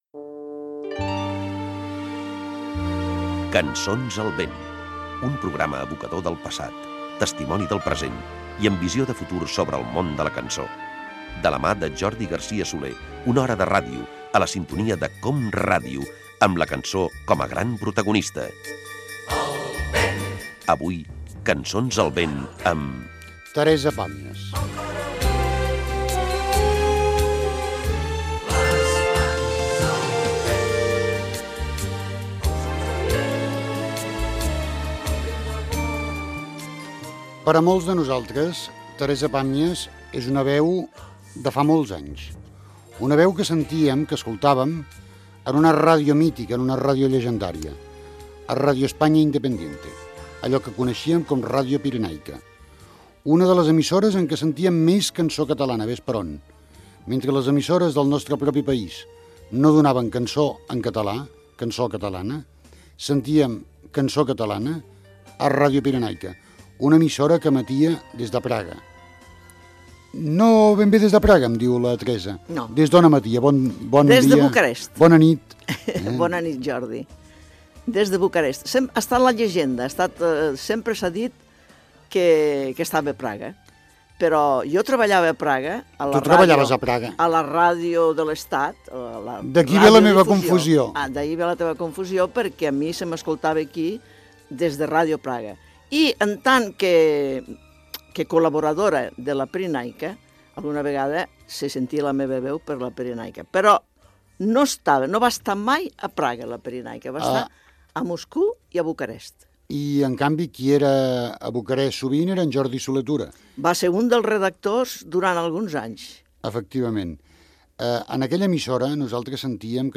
Careta del programa, presentació i entrevista a l'escriptora Teresa Pàmies que havia estat col·laboradora de Radio España Independiente, "la Pirenaica".
Entreteniment